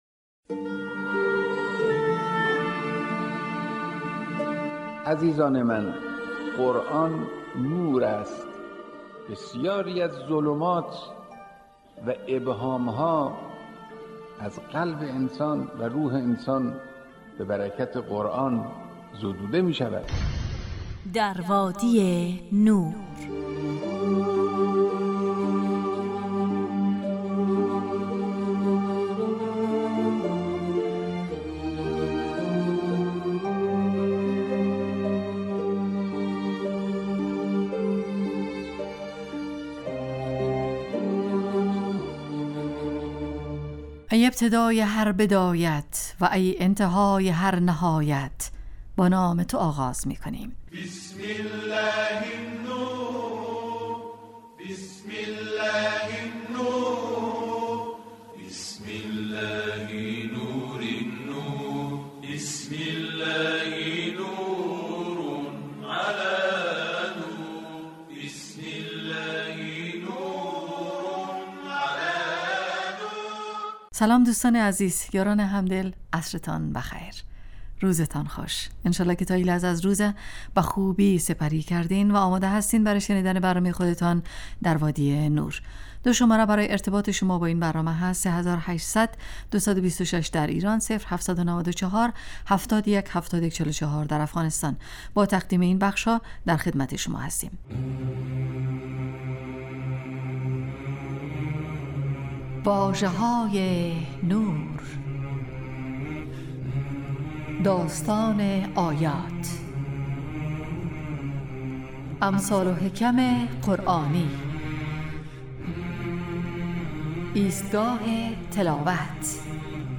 روزهای زوج: ( واژه های نور، اسماء الهی، ایستگاه تلاوت، داستان آیات، تفسیر روان و آموزه های زندگی ساز.